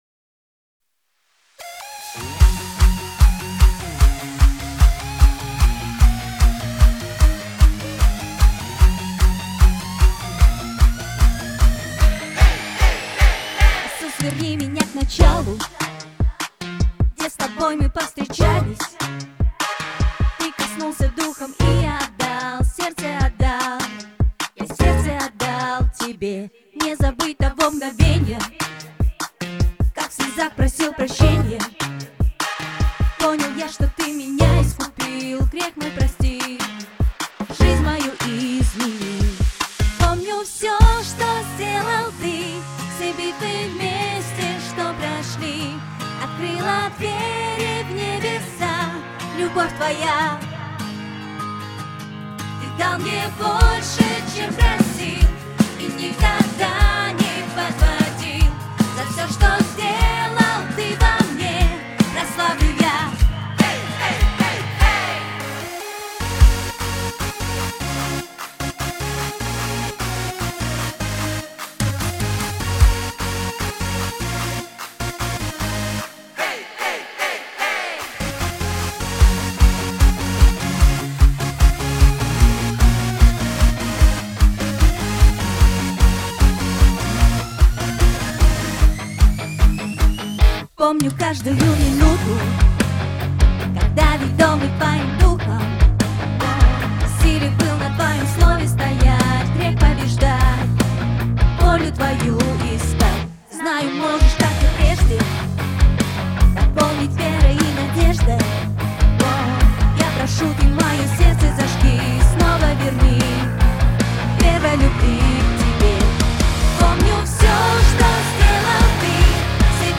181 просмотр 47 прослушиваний 0 скачиваний BPM: 150